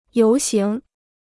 游行 (yóu xíng) Free Chinese Dictionary